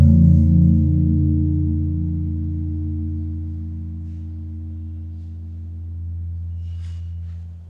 gong2.wav